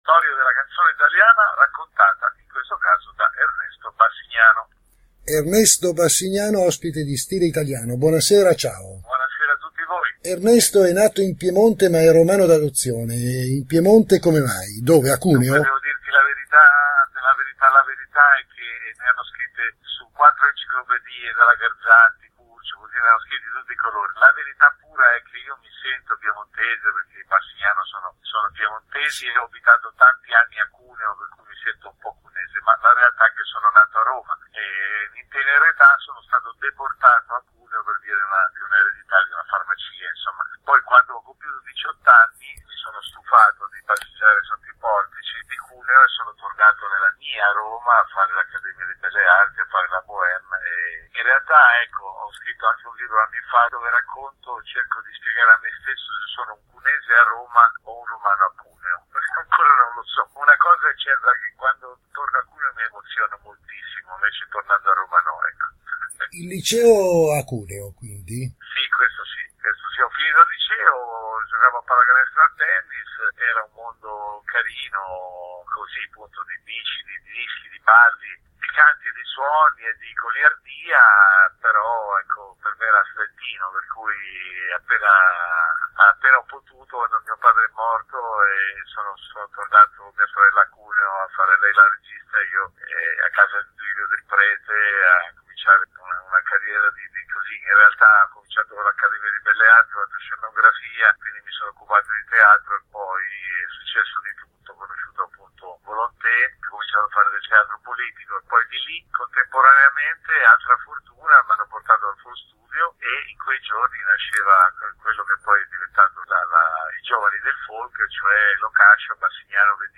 ernesto-bassignano-solo-parlato.mp3